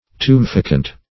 Search Result for " tumefacient" : The Collaborative International Dictionary of English v.0.48: Tumefacient \Tu`me*fa"cient\, a. [L. tumefaciens, -entis, p. pr. of tumefacere to tumefy; tumere to swell + facere to make.]